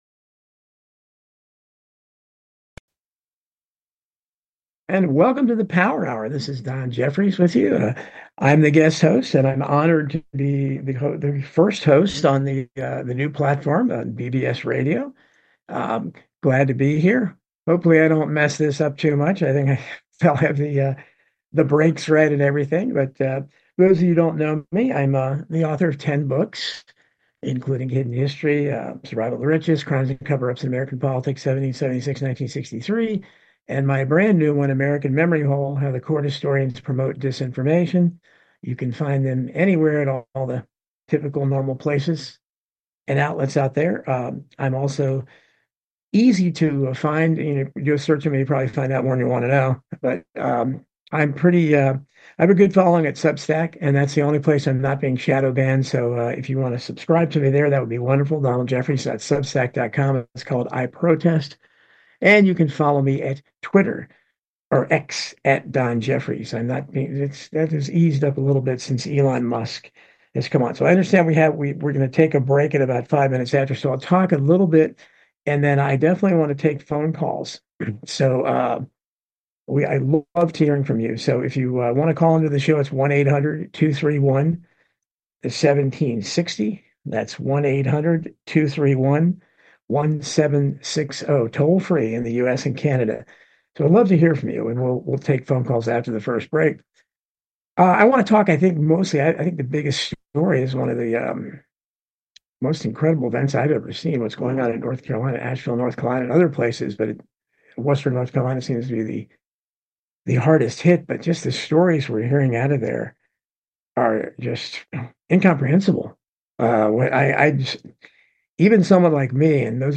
The Power Hour with Guest Hosts of Distinction